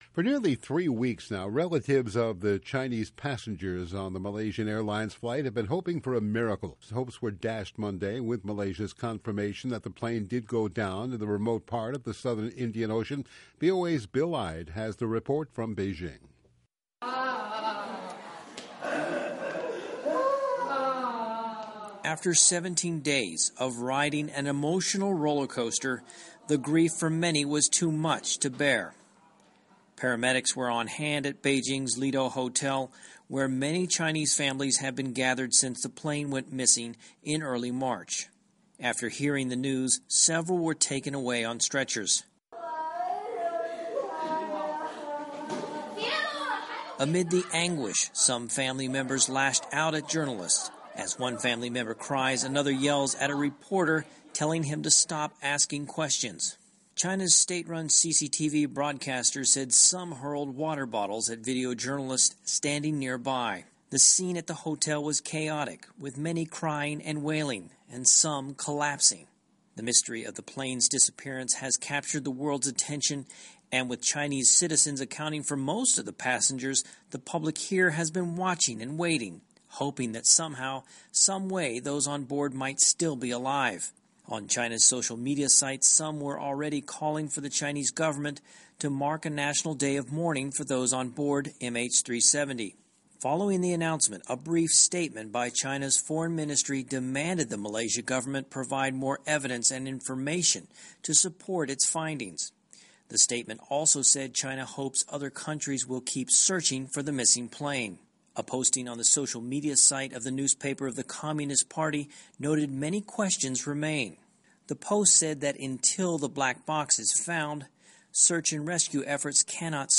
A Psychologist Specializing In Grief Counseling.